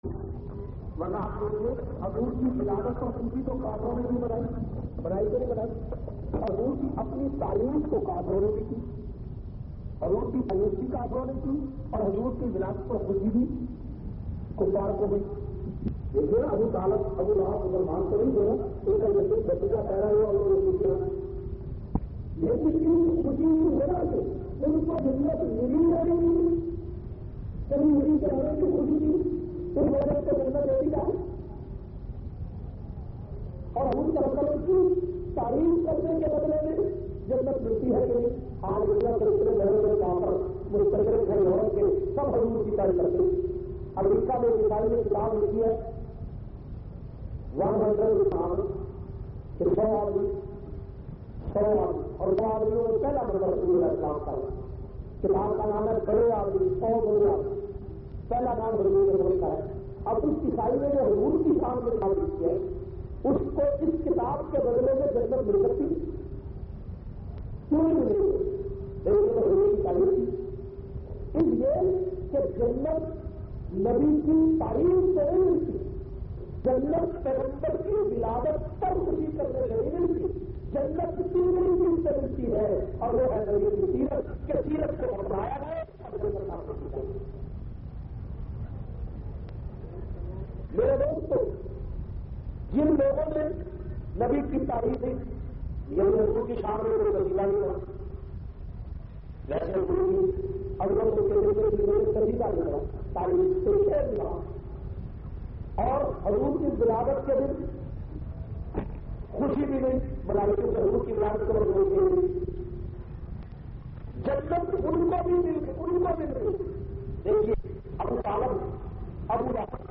397- Seerat un Nabi Jumma khutba Jamia Masjid Muhammadia Samandri Faisalabad.mp3